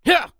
CK重击1.wav
CK重击1.wav 0:00.00 0:00.40 CK重击1.wav WAV · 35 KB · 單聲道 (1ch) 下载文件 本站所有音效均采用 CC0 授权 ，可免费用于商业与个人项目，无需署名。
人声采集素材/男2刺客型/CK重击1.wav